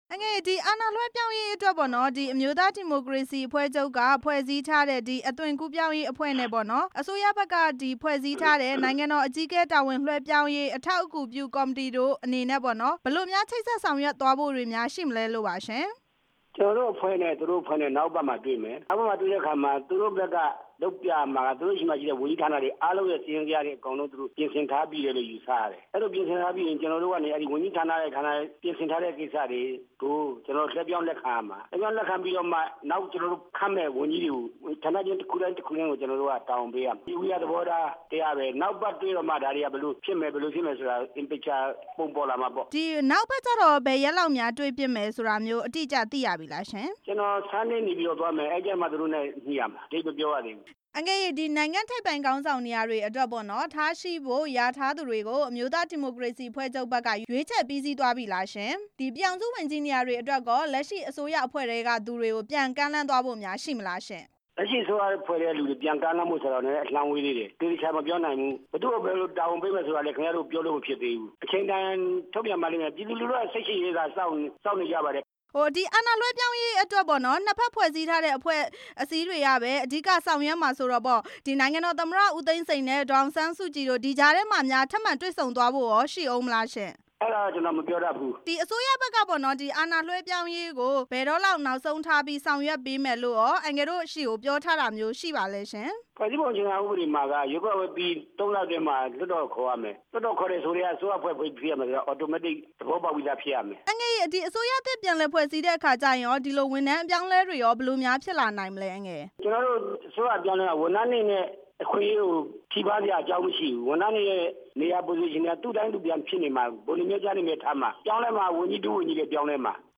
ဦးဝင်းထိန် ကို မေးမြန်းချက်